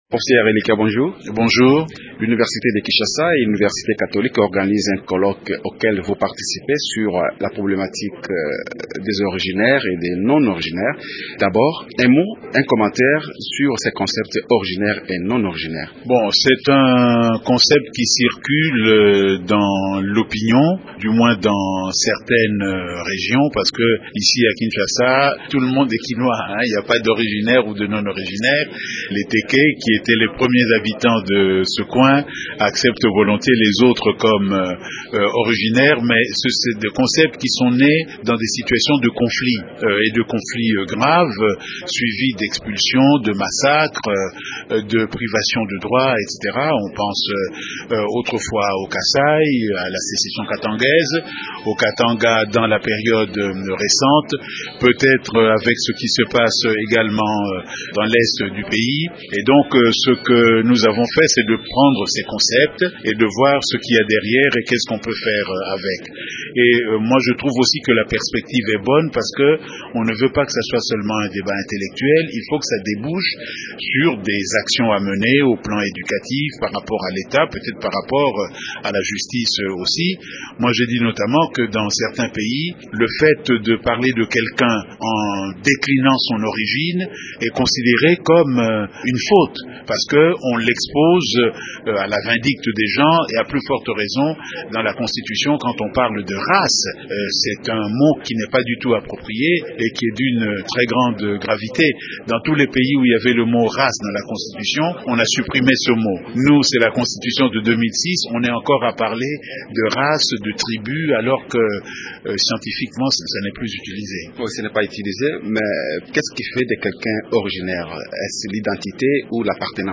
Le professeur Elikia M’bokolo est notre invité. Il aborde la question des originaires et non originaires en RDC.